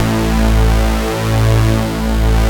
Index of /90_sSampleCDs/Roland LCDP02 Guitar and Bass/BS _Synth Bass 1/BS _Wave Bass